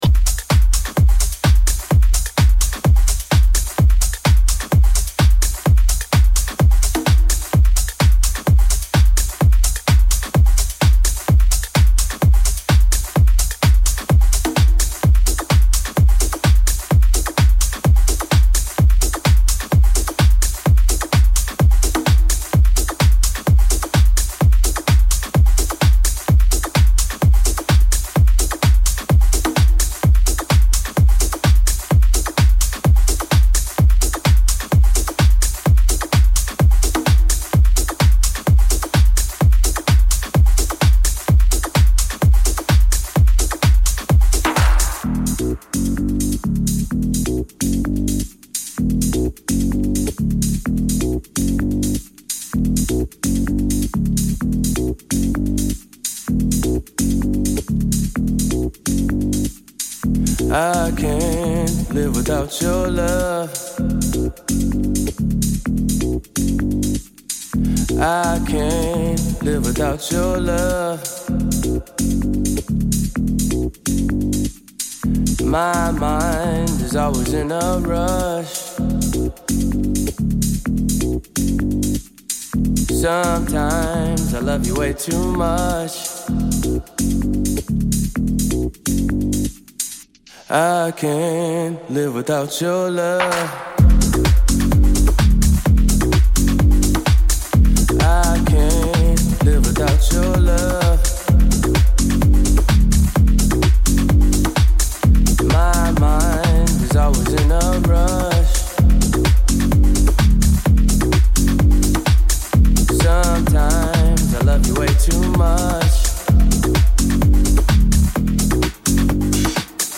Also find other EDM Livesets,